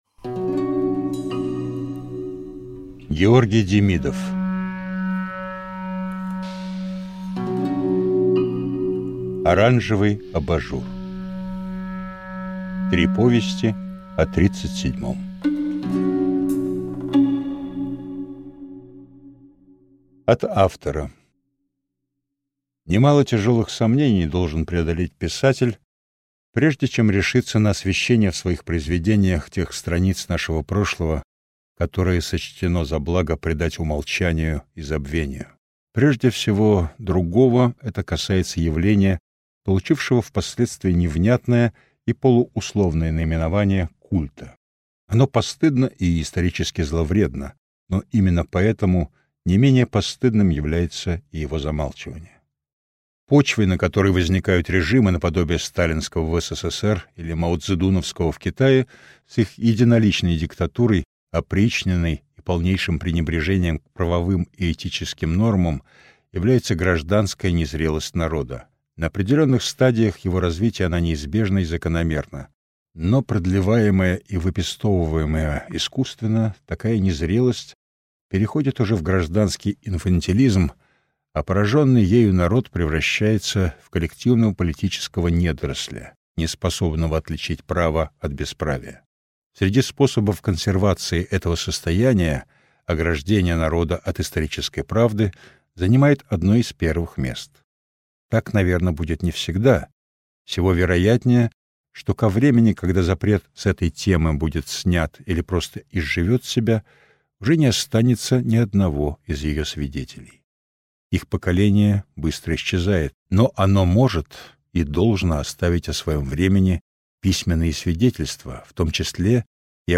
Aудиокнига Оранжевый абажур Автор Георгий Демидов Читает аудиокнигу Александр Феклистов.